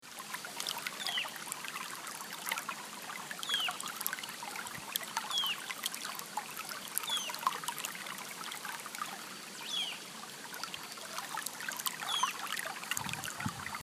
صدای جریان ملایم آب و پرنده کوچک: